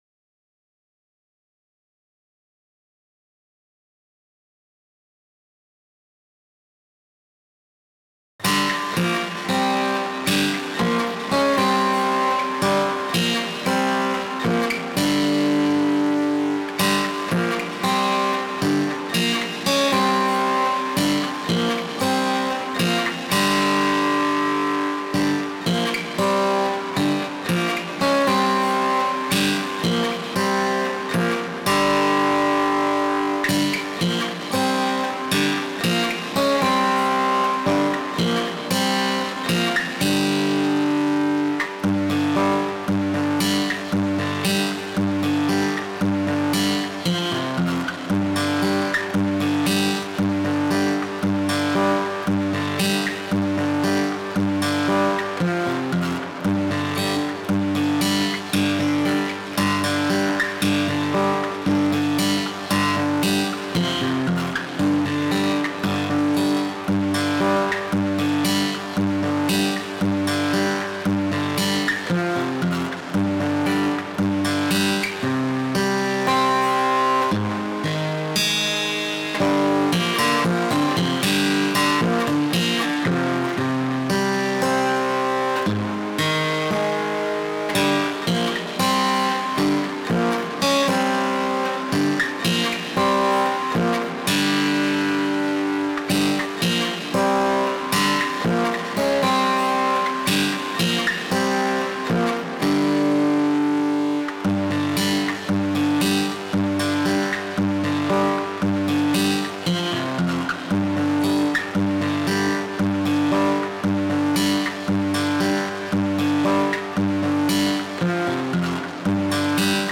Hier ein paar Klangbeispiele, die mit virtuellen Instrumenten eingespielt wurden.